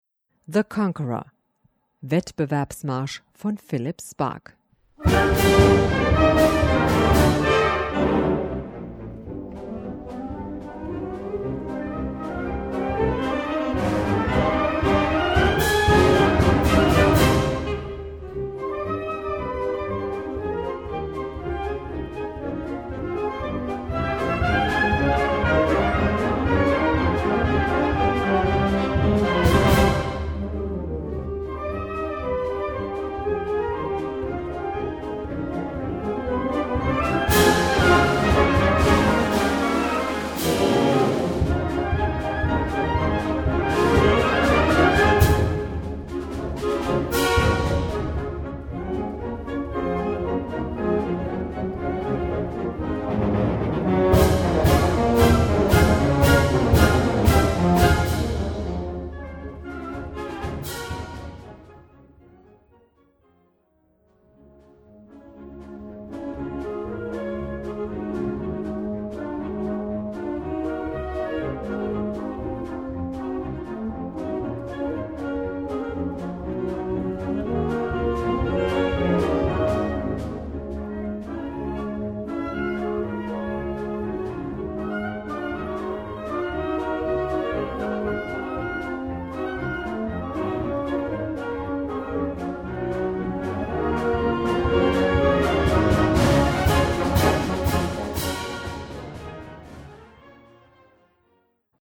Besetzung: Blasorchester